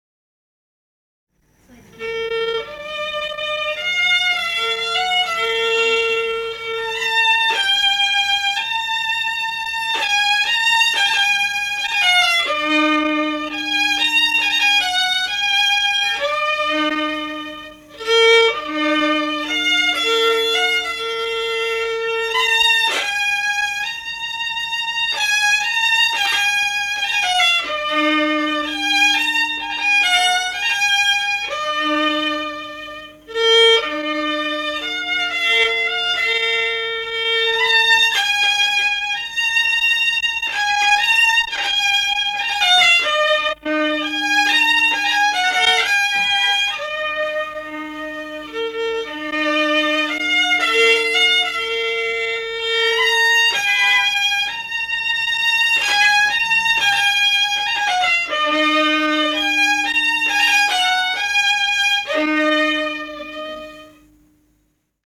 Наигрыш. "Косица моя русая"